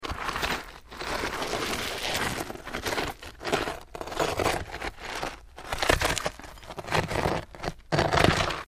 Scrape, Stone
StoneScrapesOnGrit PE442001
Stone Scrapes; On Gritty Stone And Gravel.